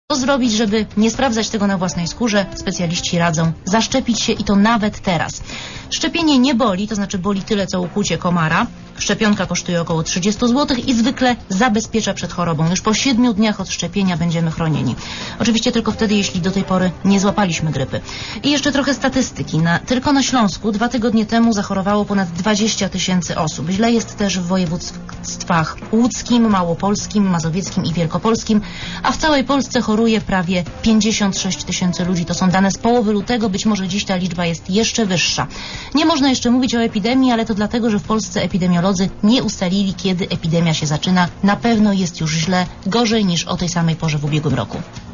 Posłuchaj relacji reportera Radia Zet (175 MB)